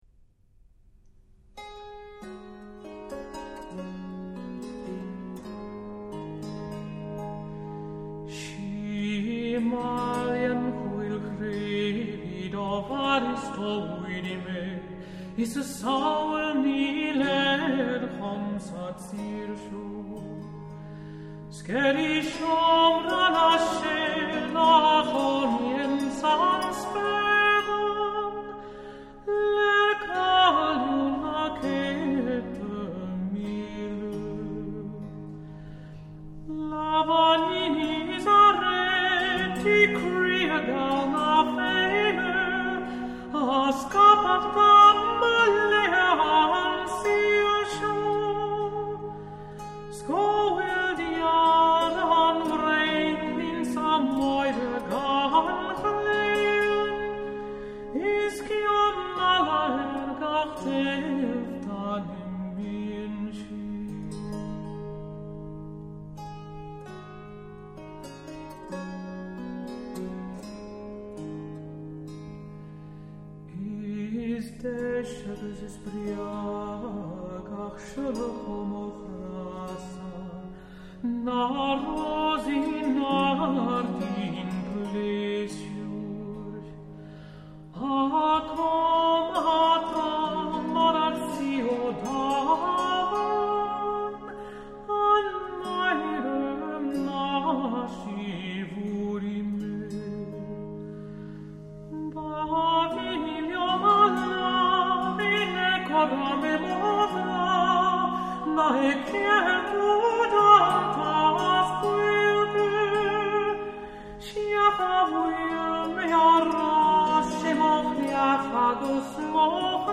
Gælic songs and dances of the 17th & 18th centuries